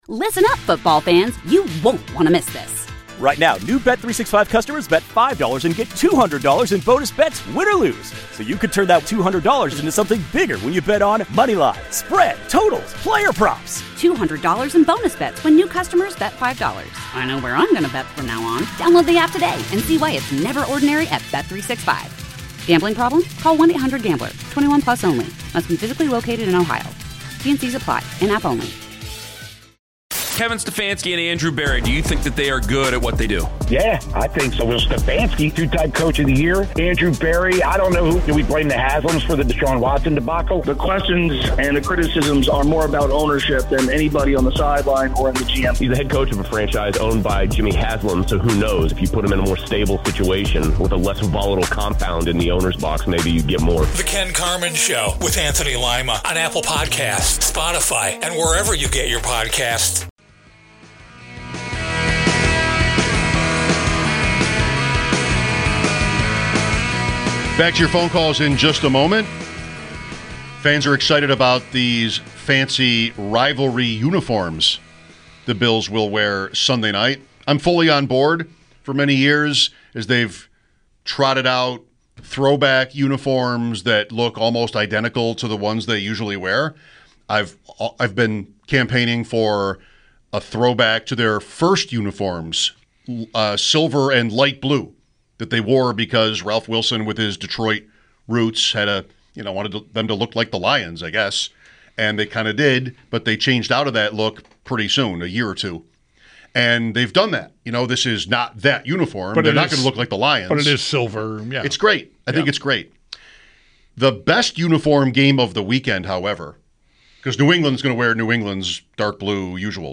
A Bills fans calls into the show wondering if the Bills can make a call for AJ BrownA Bills fans calls into the show wondering if the Bills can make a call for AJ Brown